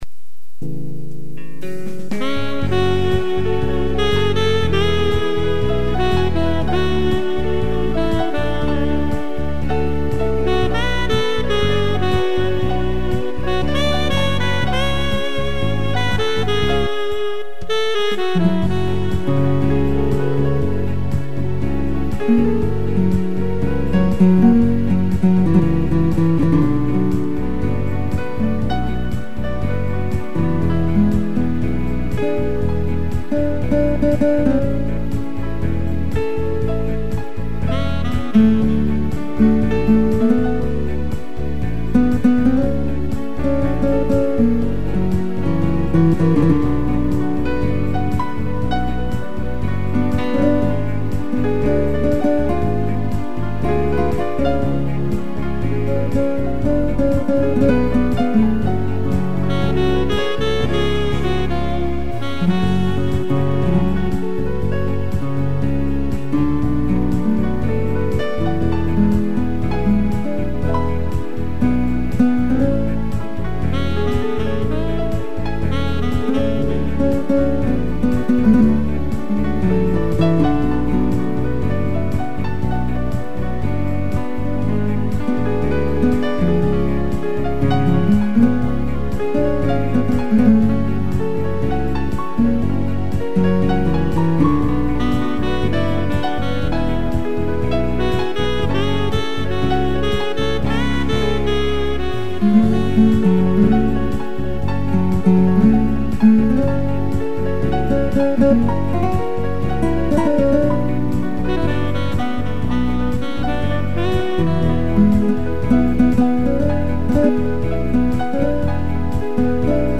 piano e sax
(instrumental)